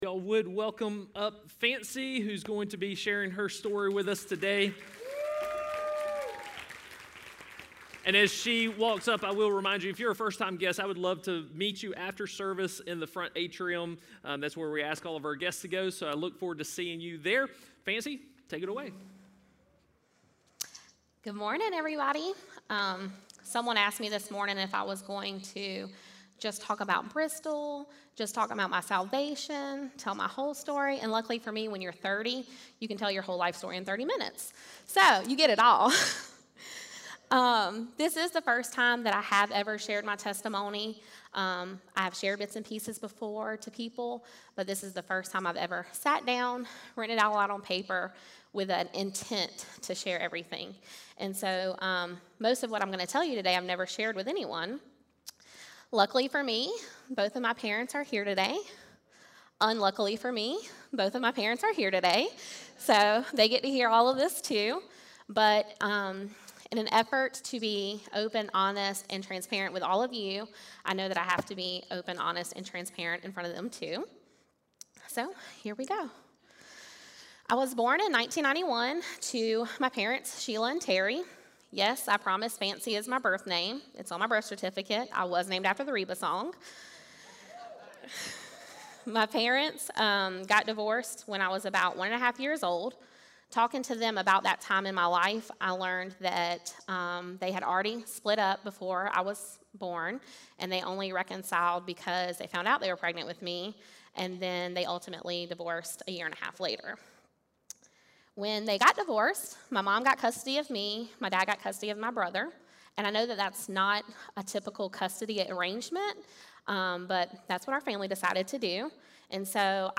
Everyone has a story, listen to these followers of Jesus share their story on life before and after Jesus.